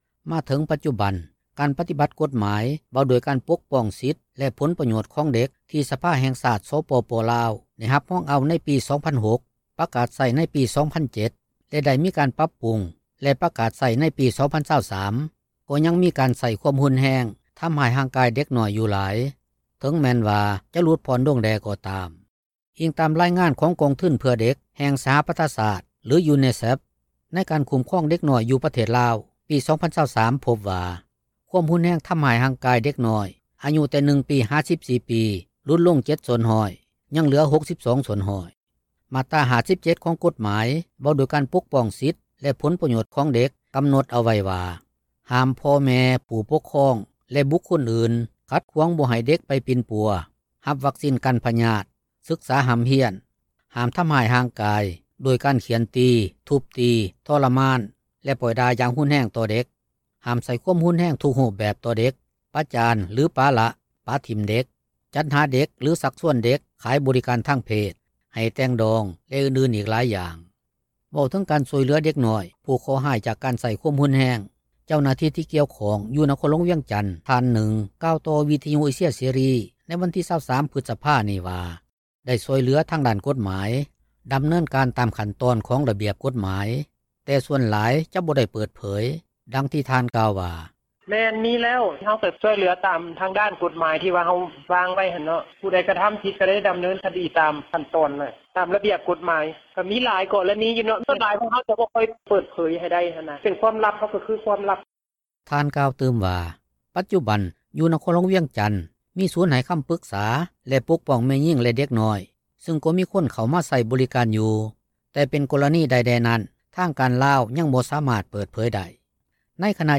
ໃນຂະນະດຽວກັນ ເຈົ້າໜ້າທີ່ ທີ່ເຮັດວຽກປົກປ້ອງແມ່ຍິງ ແລະເດັກນ້ອຍ ຢູ່ແຂວງສະຫວັນນະເຂດ ນາງນຶ່ງກໍເວົ້າໃນມື້ດຽວກັນນີ້ວ່າ ປັດຈຸບັນ ຖືວ່າ ຄວາມຮຸນແຮງຕໍ່ເດັກນ້ອຍ ຫລຸດລົງຫລາຍແລ້ວ ບໍ່ຄືແຕ່ກ່ອນ ຍ້ອນວ່າ ຢູ່ແຂວງນີ້ ມີສູນໃຫ້ຄໍາປຶກສາ ປົກປ້ອງແມ່ຍິງ ແລະເດັກນ້ອຍ, ບາງກໍລະນີ ກໍເຂົ້າມາຂໍຄໍາປຶກສາ ຮວມທັງບັນຫາທີ່ເກີດຂື້ນໃນຄອບຄົວ.
ກ່ຽວກັບການປົກປ້ອງເດັກນ້ອຍ ຈາກການໃຊ້ຄວາມຮຸນແຮງນີ້ ປະຊາຊົນນາງນຶ່ງ ທີ່ເປັນຄົນຊົນເຜົ່າມົ້ງ ຢູ່ແຂວງຫົວພັນ ເວົ້າວ່າ ດຽວນີ້ ກໍຖືວ່າຫລຸດລົງຫລາຍແລ້ວ ເກືອບຈະບໍ່ມີການໃຊ້ຄວາມຮຸນແຮງຕໍ່ເດັກນ້ອຍ ແລະແມ່ຍິງເລີຍ ເພາະທາງການມີກົດໝາຍຄຸ້ມຄອງ ເຮັດໃຫ້ຫລາຍຄົນຮັບຮູ້ ແລ້ວກໍຮູ້ສຶກຢ້ານຖືກລົງໂທດ ນອກຈາກຄອບຄົວ ທີ່ມີການກິນເຫລົ້າ ເສບຢາເສບຕິດ ຊຶ່ງອາດຈະມີການໃຊ້ຄວາມຮຸນແຮງກັບລູກ ກັບເມຽຢູ່.